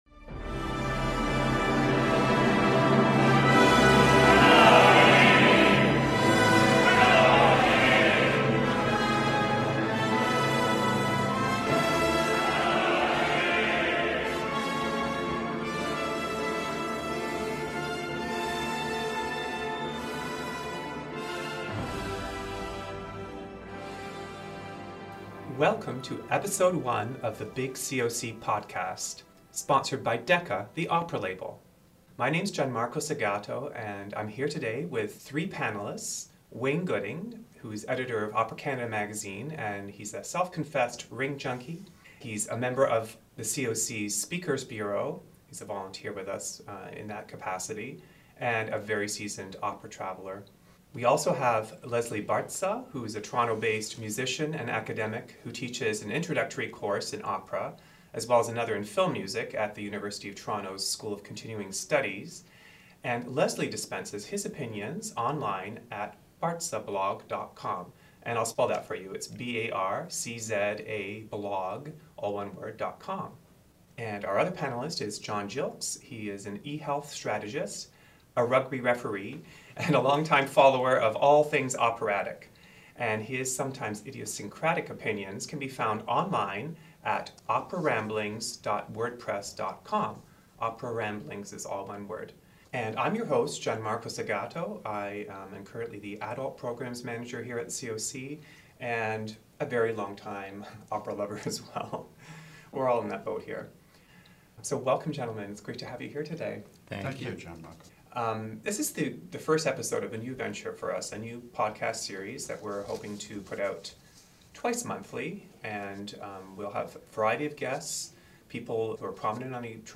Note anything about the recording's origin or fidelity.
Episode one includes round table discussions about the controversy at Bayreuth, thoughts on marketing classical music in Toronto and the question of operetta in an opera house, especially considering the upcoming production of Die Fledermaus by the COC.